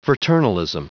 Prononciation du mot fraternalism en anglais (fichier audio)